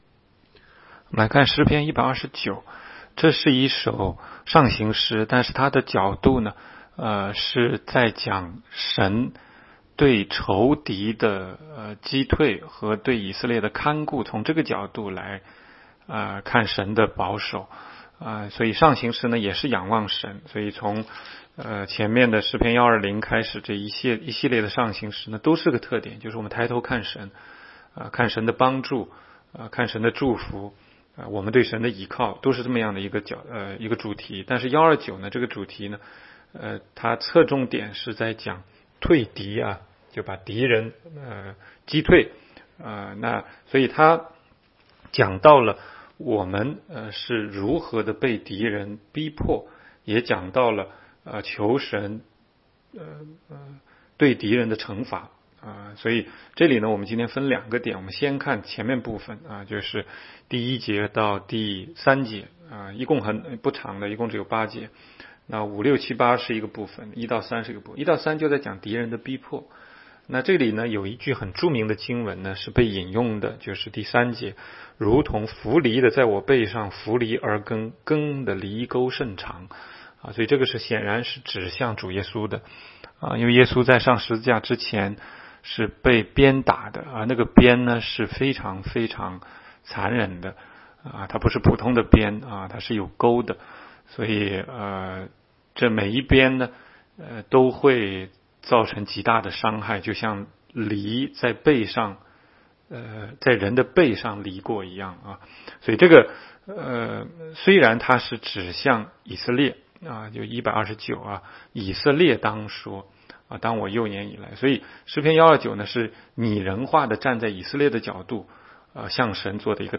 16街讲道录音 - 每日读经 -《 诗篇》129章